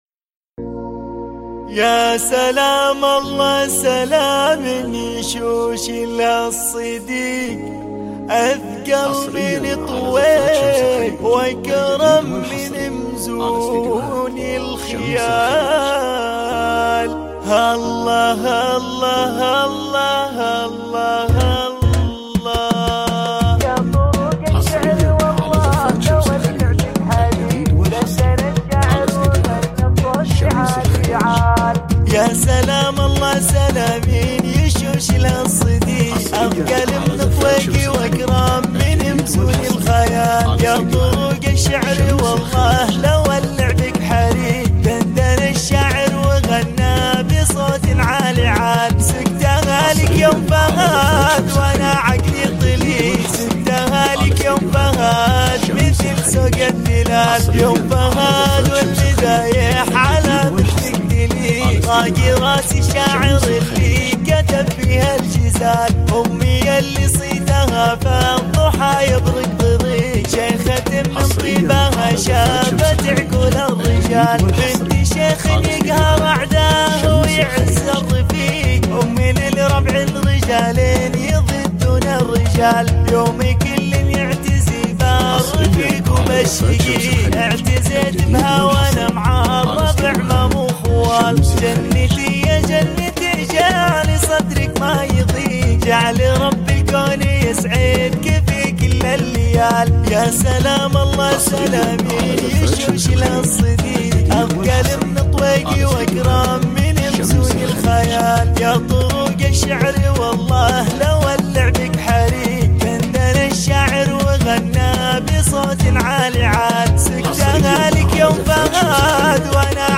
زفات موسيقى